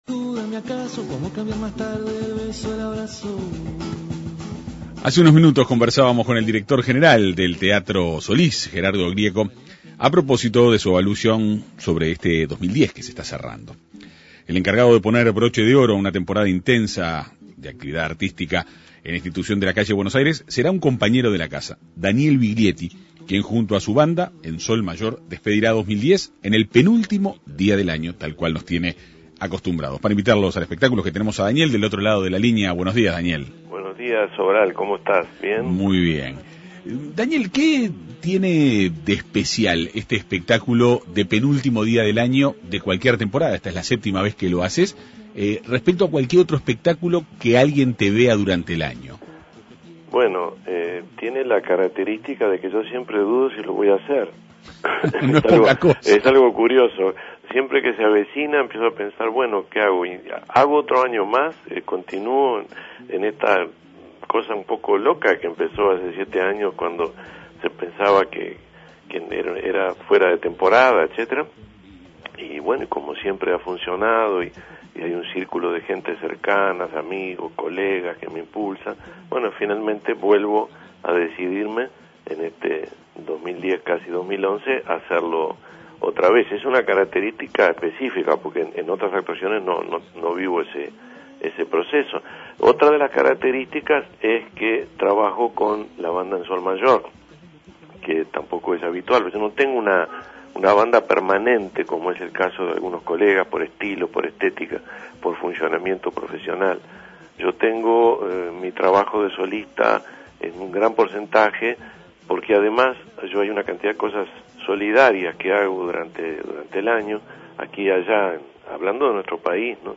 El encargado de poner el broche de oro a una temporada de intensa actividad artística al Teatro Solís será Daniel Viglietti, quien junto a su "Banda en Sol Mayor" despedirá el 2010 el penúltimo día del año. El músico conversó en la Segunda Mañana de En Perspectiva.